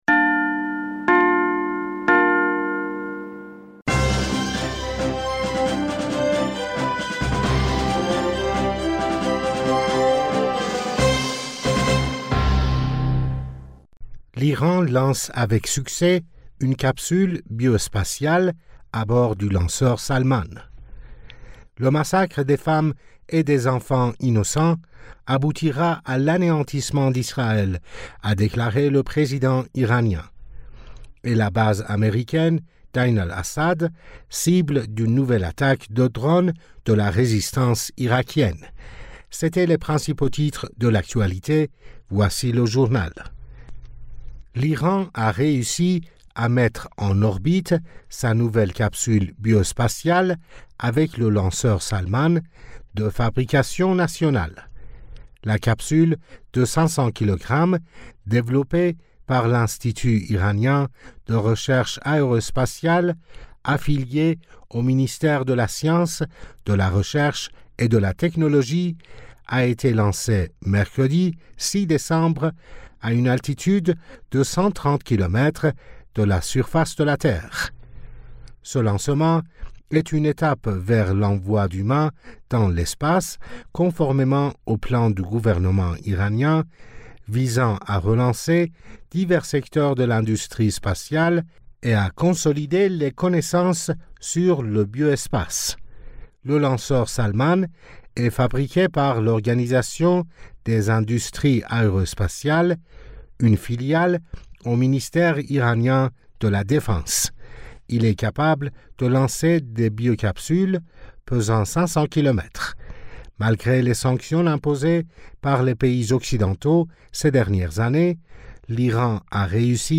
Bulletin d'information du 05 Decembre 2023